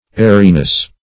Airiness \Air"i*ness\, n.
airiness.mp3